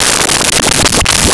Game Over 01
Game Over 01.wav